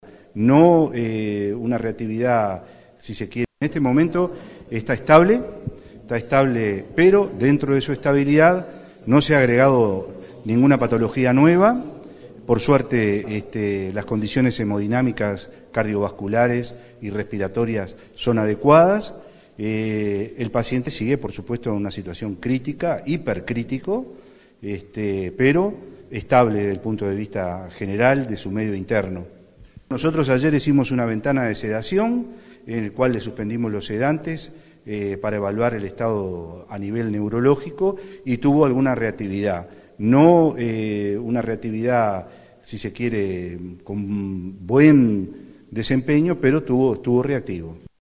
contó en rueda de prensa antes del traslado que el estado de Batlle sigue siendo “hipercrítico”